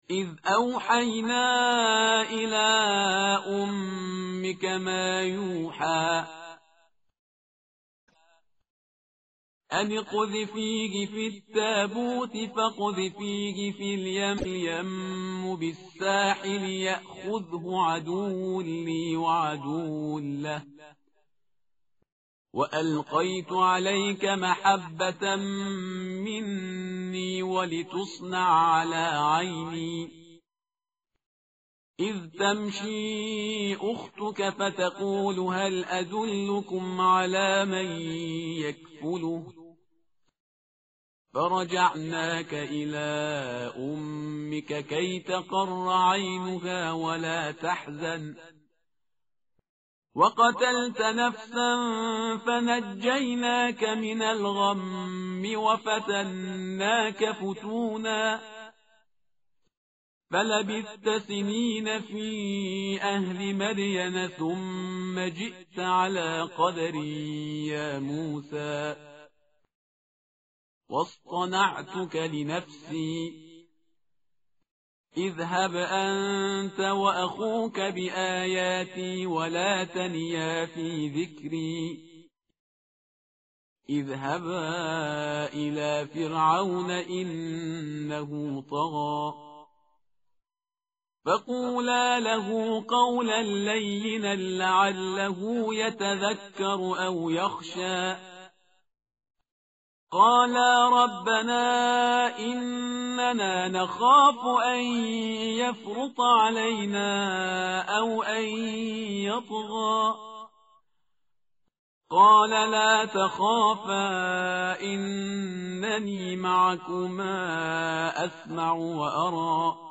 tartil_parhizgar_page_314.mp3